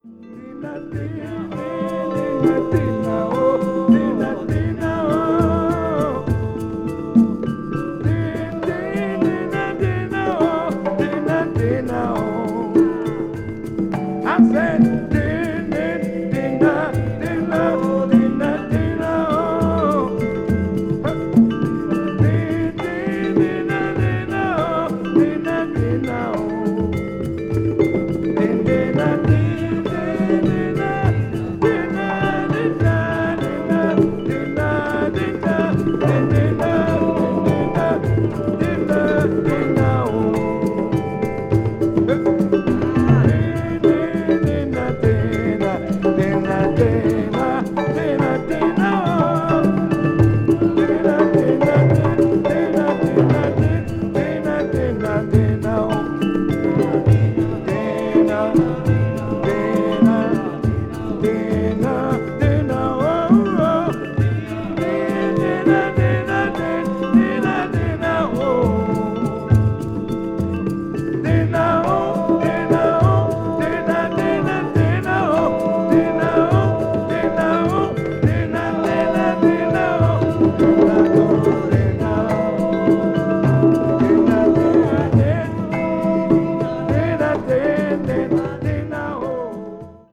media : VG+/VG+(薄いスリキズによる軽いチリノイズ/プチノイズが入る箇所あり)
もちろんライヴ・レコーディングです。
avant-garde   ethnic jazz   experimental   world music